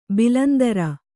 ♪ bilandara